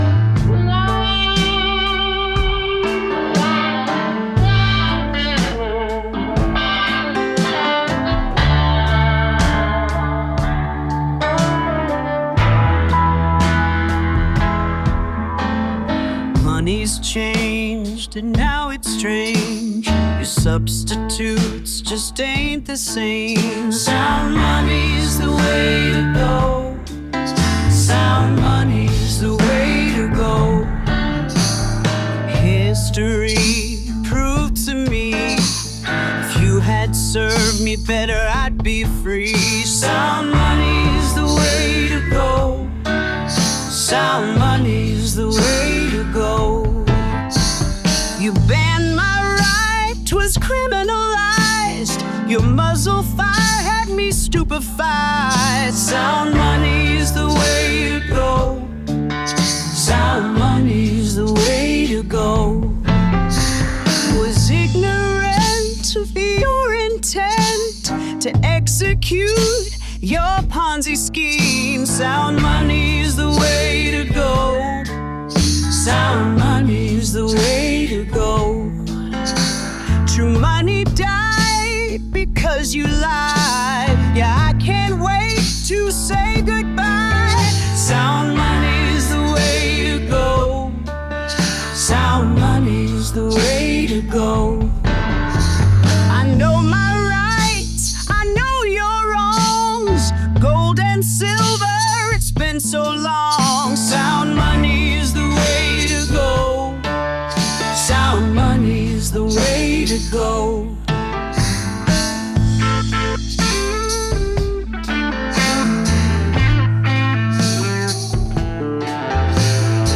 Female vocalist